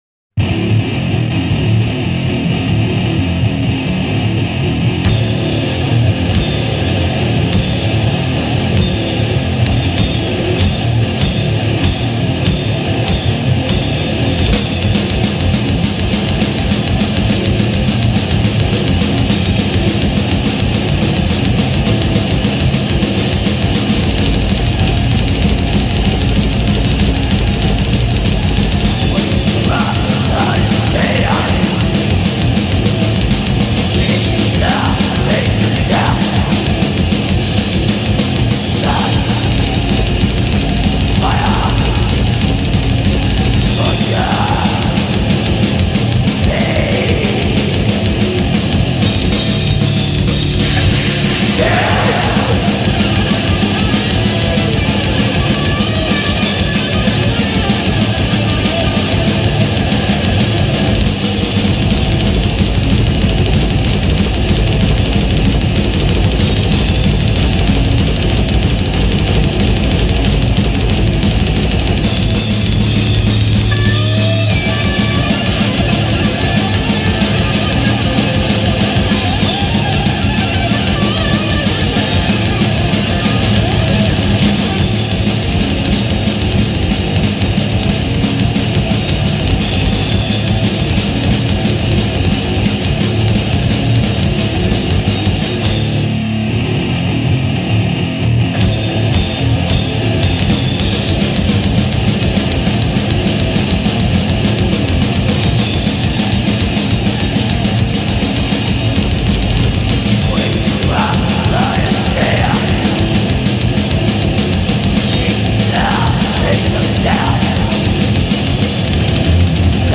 گوش کنید و لذت ببرید با ووکال دد
black metal